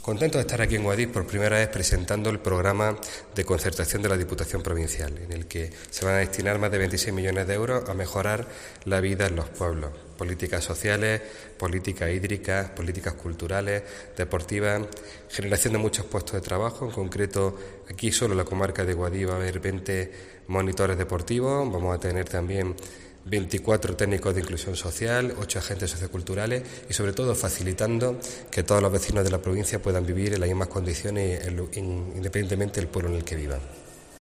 Francis Rodríguez, Presidente de la Diputación